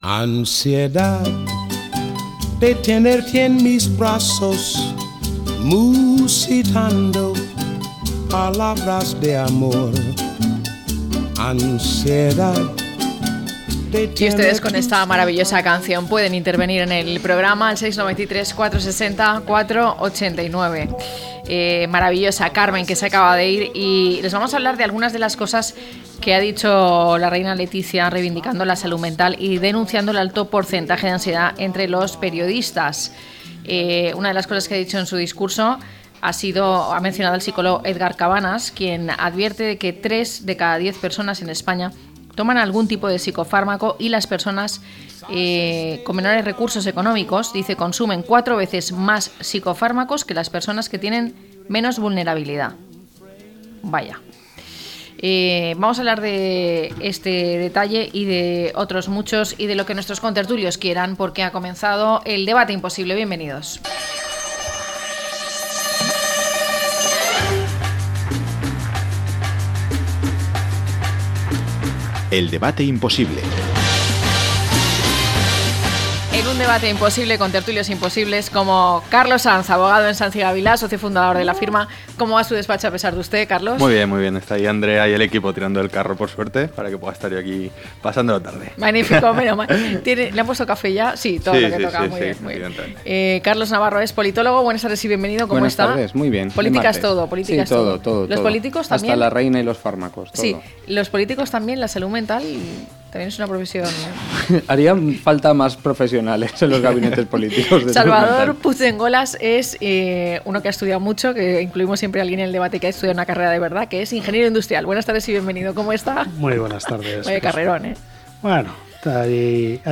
1022-LTCM-DEBATE.mp3